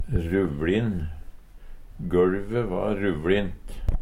Høyr på uttala Ordklasse: Adjektiv Kategori: Karakteristikk Attende til søk